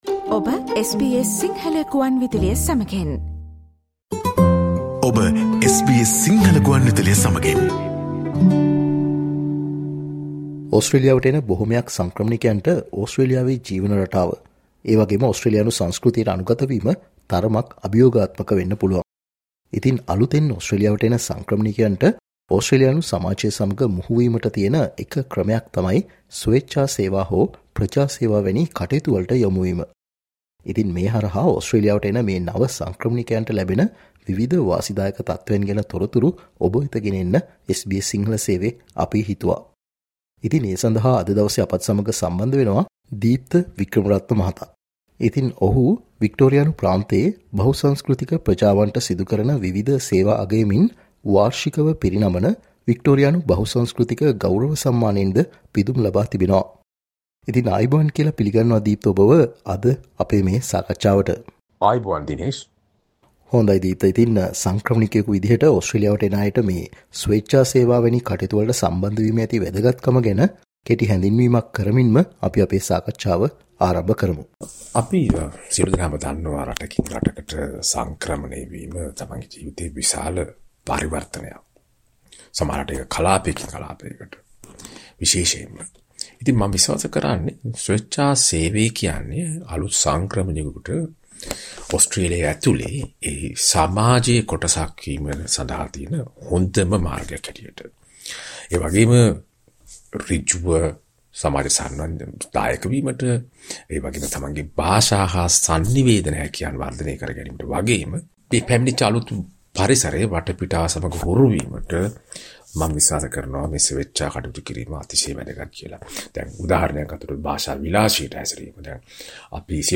ඔස්ට්‍රේලියාවට සංක්‍රමණය වන නව සංක්‍රමණිකයින්ට ඕස්ට්‍රේලියාව තුලදී ස්වේච්ඡා සේවයේ යෙදීමේ ඇති වැදගත් කම ගැන SBS සිංහල සේවය සිදුකල සාකච්චාවට සවන් දෙන්න